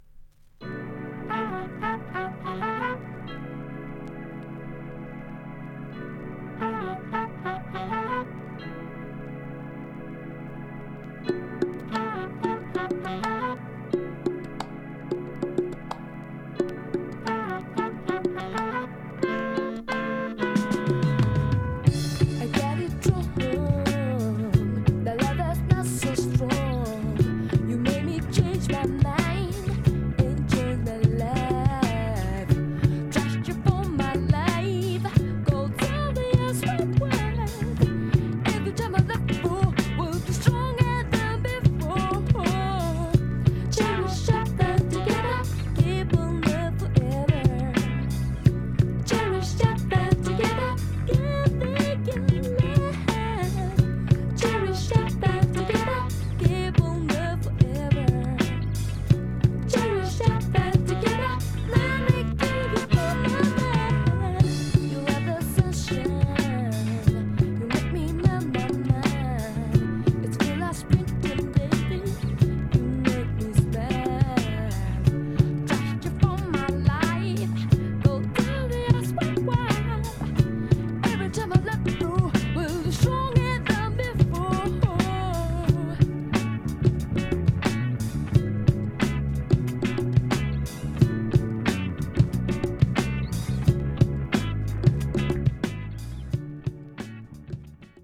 90's ジャパニーズ・フリー・ソウルの決定打♪